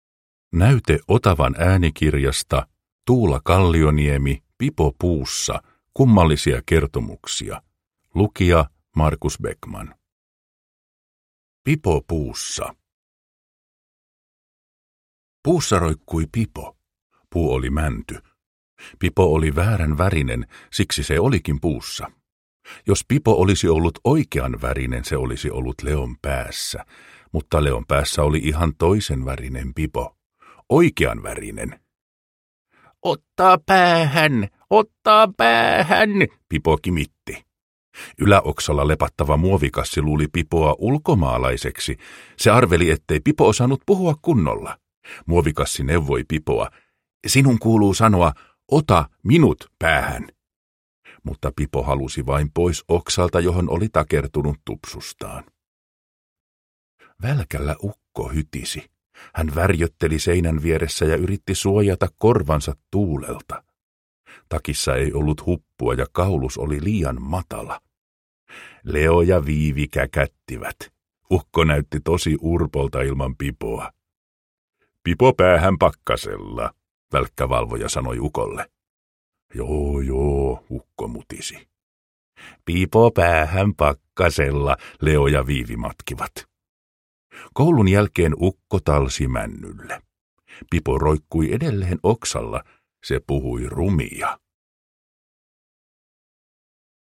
Pipo puussa – Ljudbok